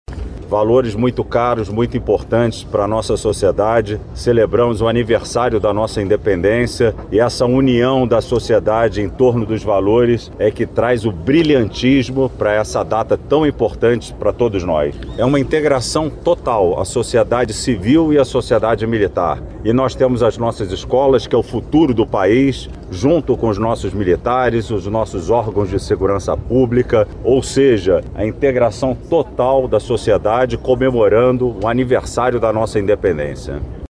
Ricardo José Nigri, general de divisão da 5ª Divisão de Exército, disse que o desfile cívico-militar celebra uma data muito especial, carregada de valores.